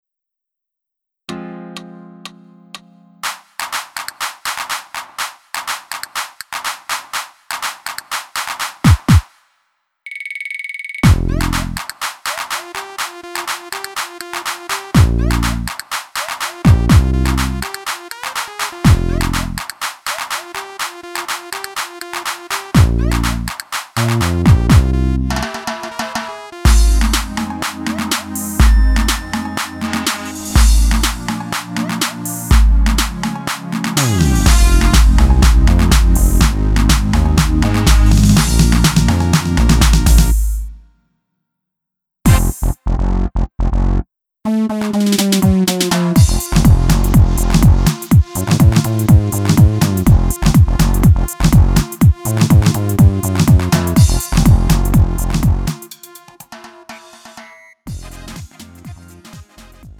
음정 -1키 3:22
장르 가요 구분